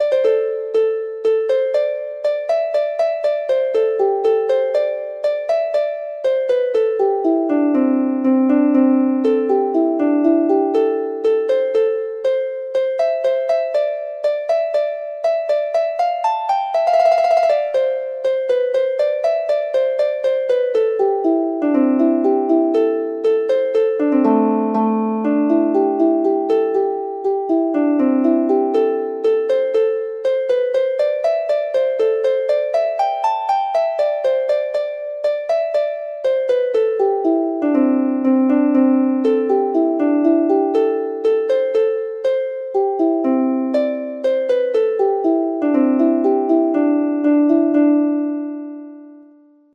Harp version
Harp  (View more Easy Harp Music)
Traditional (View more Traditional Harp Music)